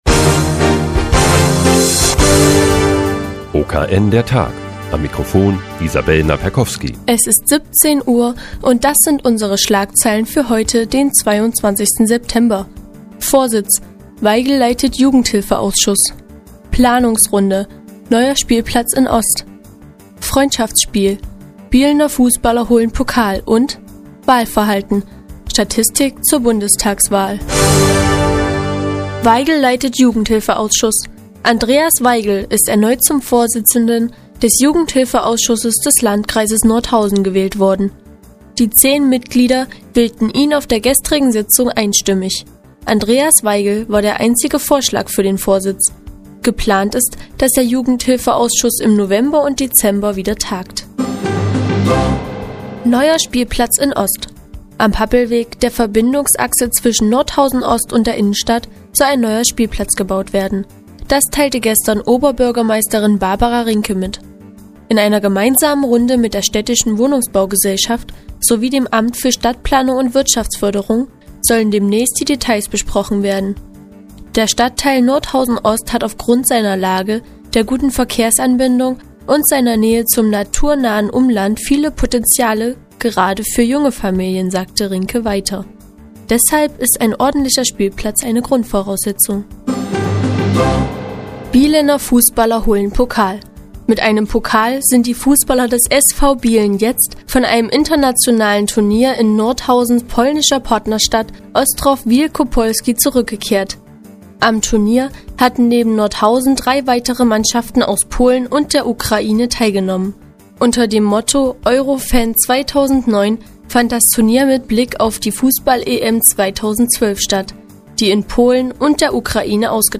Die tägliche Nachrichtensendung des OKN ist nun auch in der nnz zu hören. Heute mit einer Wahl im Jugendhilfeausschuss und erfolgreichen Fußballern.